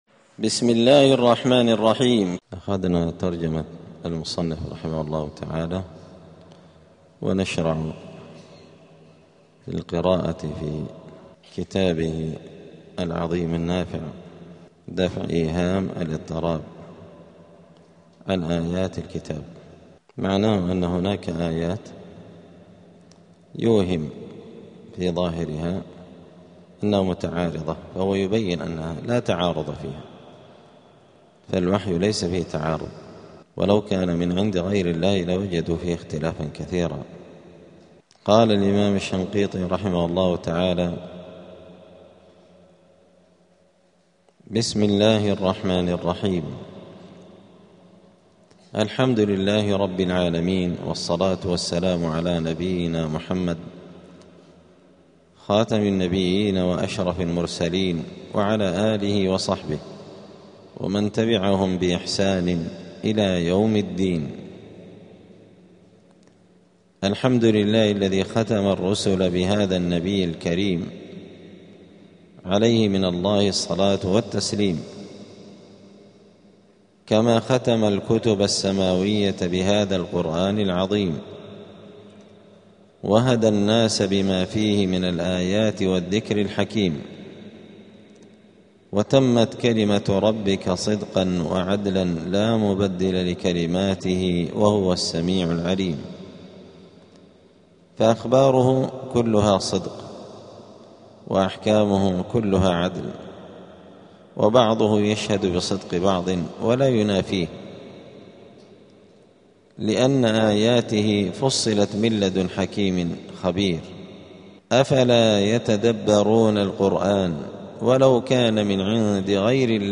*الدرس الثالث (3) بداية الكتاب {سورة البقرة}.*